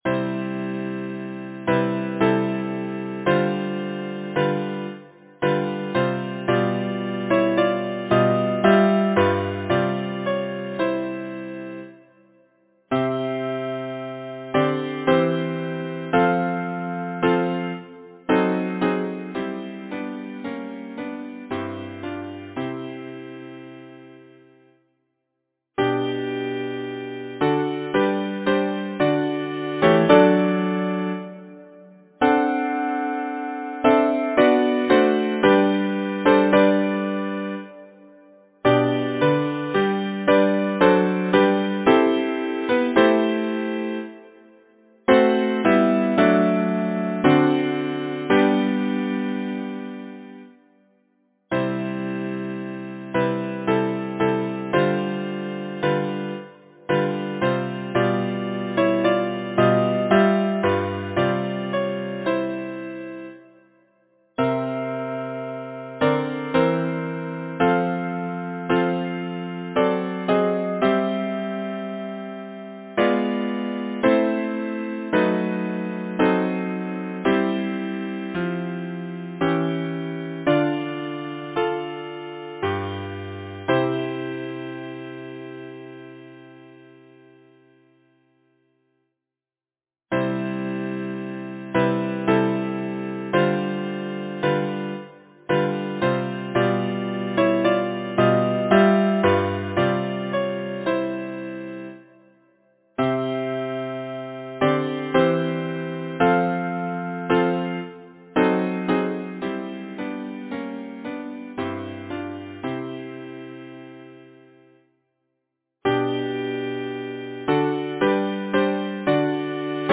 Title: Requiem Song Composer: Arnold Johann Gantvoort Lyricist: Number of voices: 4vv Voicing: SATB Genre: Secular, Partsong
Language: English Instruments: A cappella